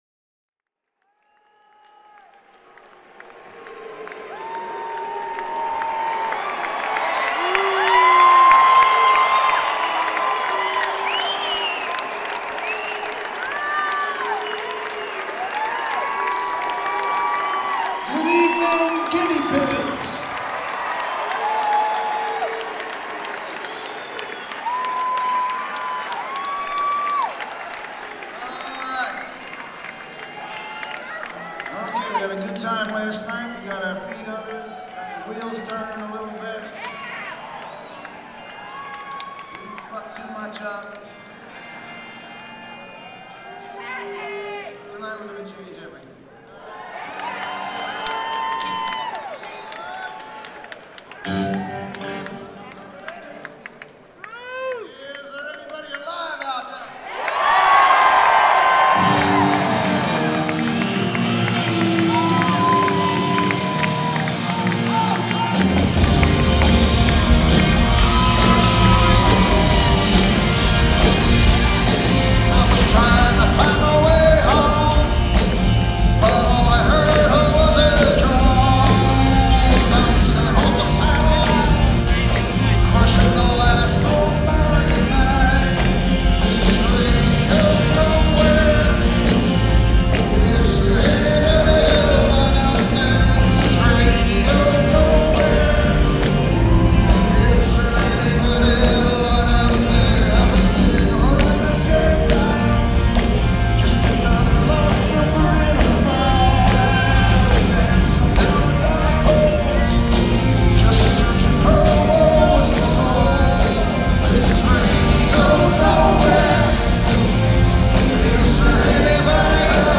un bel concerto
presso la Convention Hall, Asbury Park, New Jersey